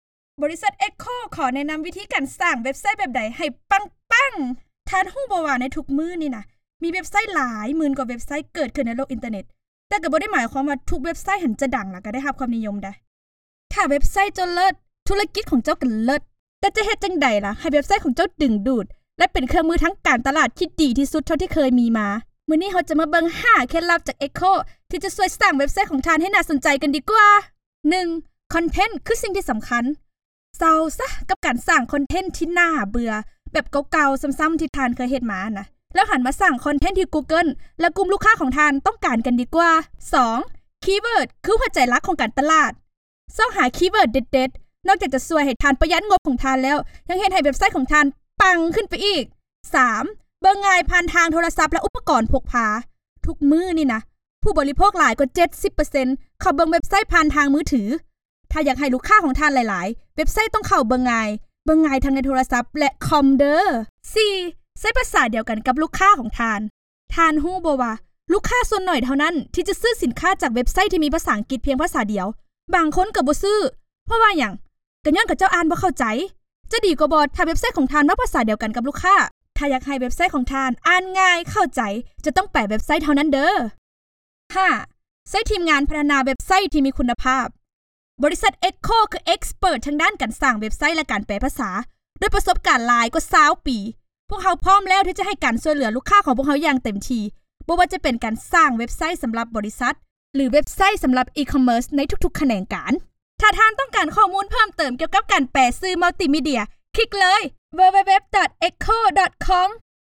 Voiceover Artists
EQHO provides multi-language solutions from its in-house recording facilities
Laos Female
COMMERCIAL